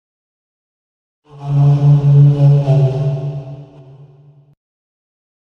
Здесь собраны реалистичные рычания, шаги и крики различных видов древних рептилий.
Звук и крик брахиозавра